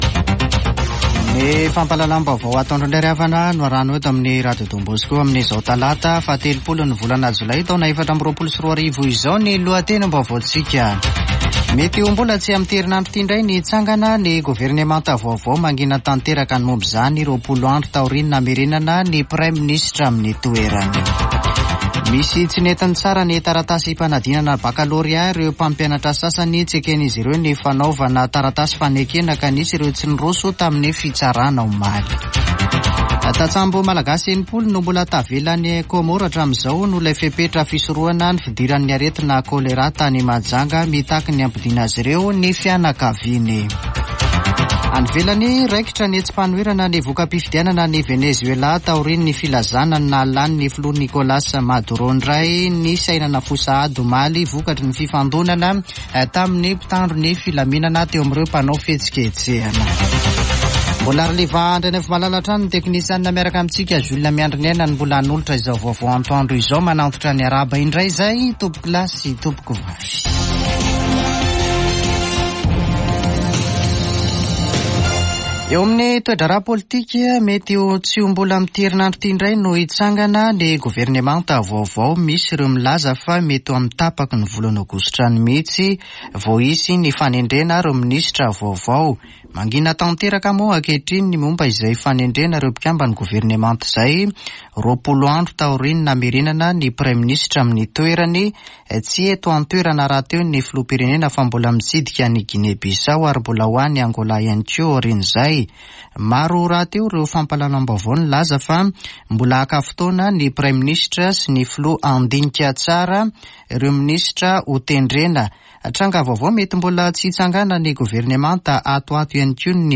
[Vaovao antoandro] Talata 30 jolay 2024